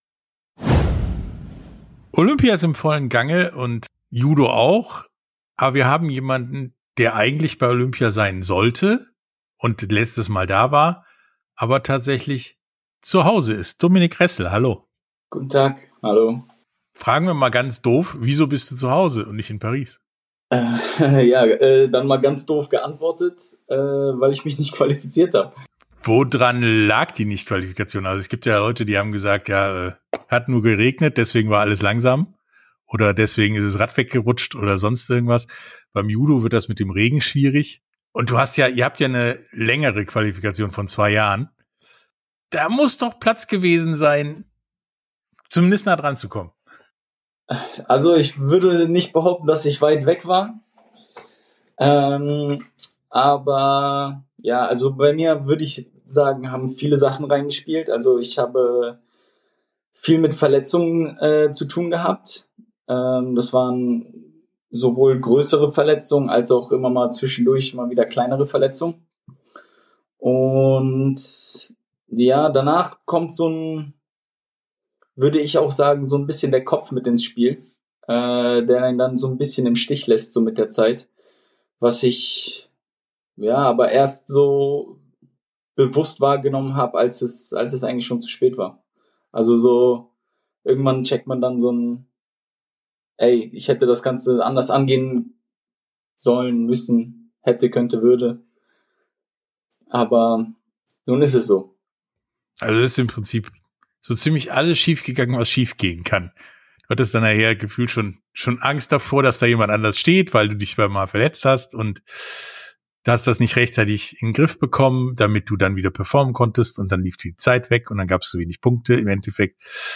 Sportstunde - Interview komplett, Dominic Ressel Judo ~ Sportstunde - Interviews in voller Länge Podcast